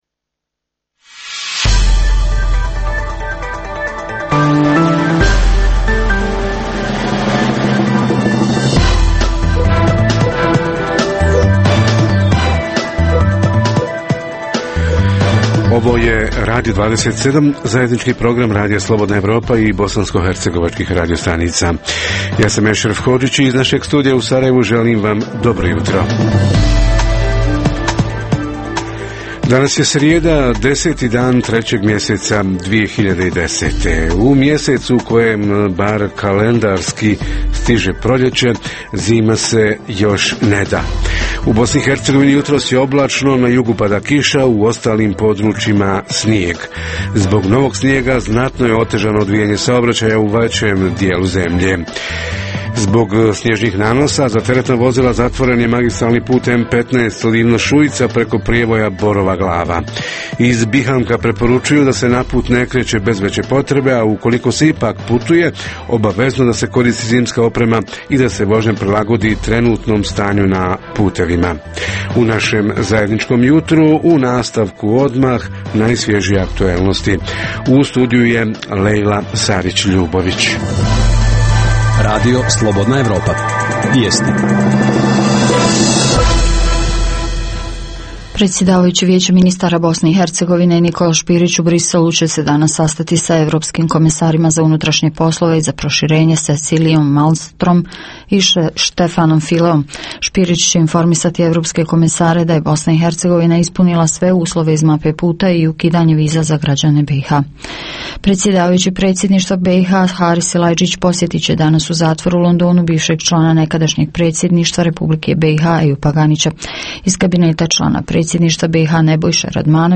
Zajednička tema: građevinari uoči nove sezone – kako su preživjeli prethodnu i kako se održati u predstojećoj građevinskoj sezoni? Reporteri iz cijele BiH javljaju o najaktuelnijim događajima u njihovim sredinama.